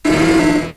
Play, download and share Quagsire Cry original sound button!!!!
quagsire-cry.mp3